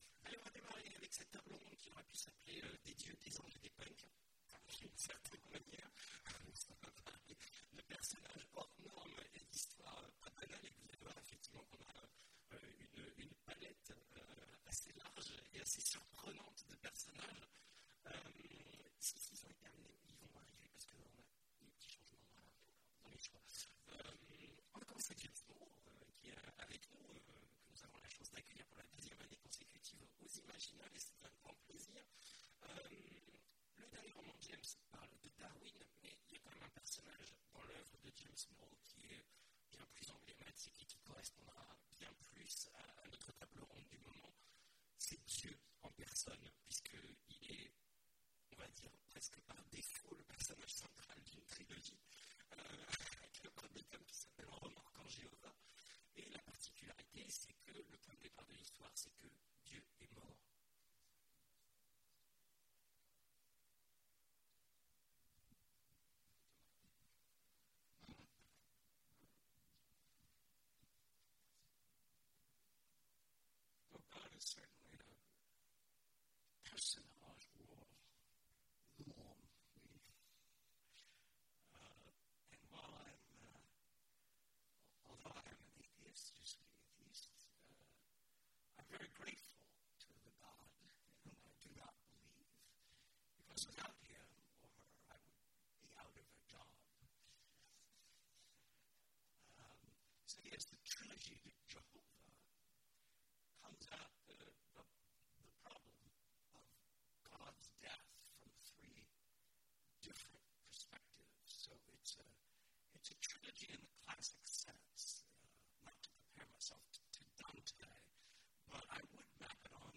Mots-clés Aventure Conférence Partager cet article